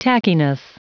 Prononciation du mot tackiness en anglais (fichier audio)
Prononciation du mot : tackiness